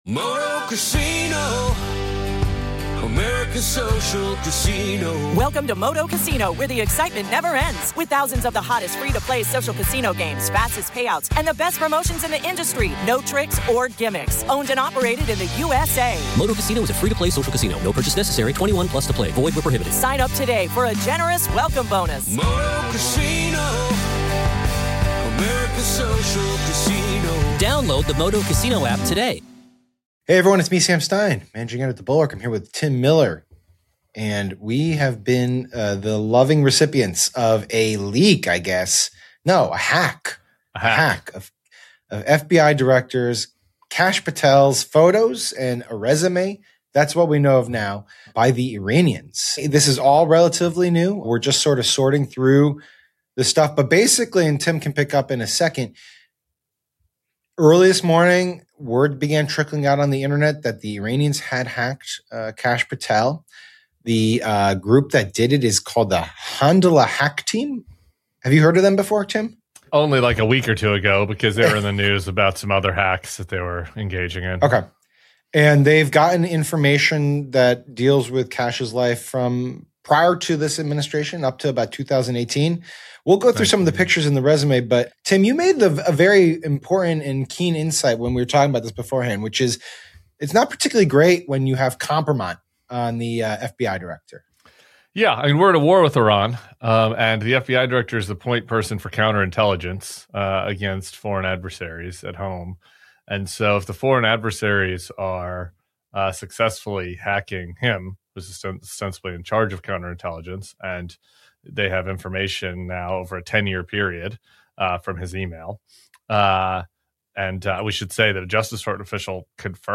Sam Stein and Tim Miller give their takes on the latest fiasco for FBI Director Kash Patel after Iran-linked hackers breached his email and released a trove of personal messages and photos. From embarrassing photos during a trip to Cuba to serious counterintelligence concerns, it’s another brutal moment for a guy already struggling to prove he’s up for the job.